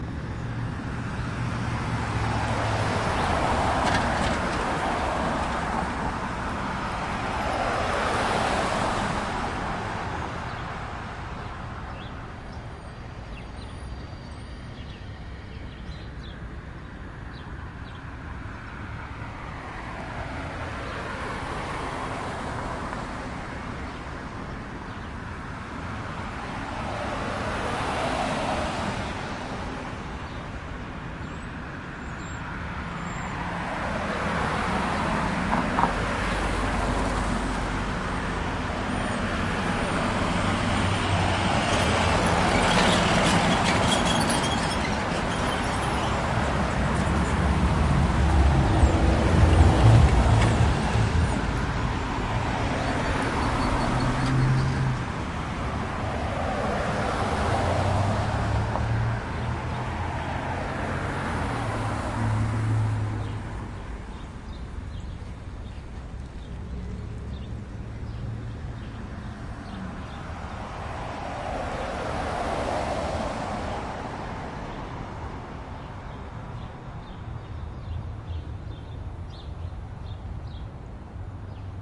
通过交通01
描述：从右到左的交通记录，背景是鸟类。用Zoom H4n Handy Recorder录制。从峰值6dB归一化为0dB。
Tag: 郊区 城区 现场记录 传递 背景 街道 交通 道路 鸟类 汽车